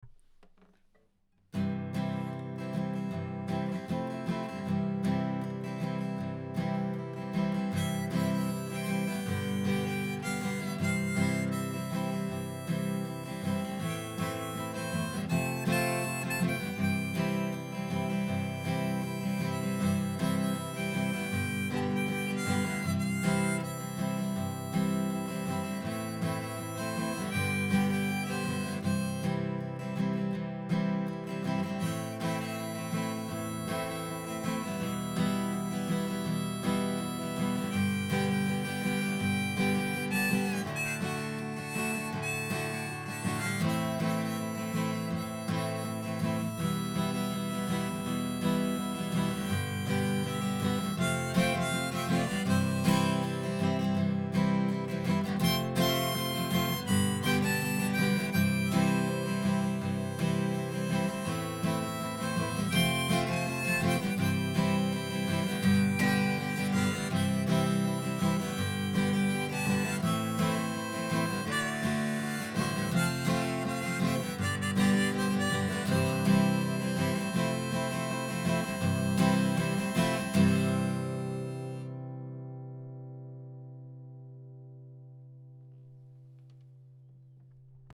guitare n°2 :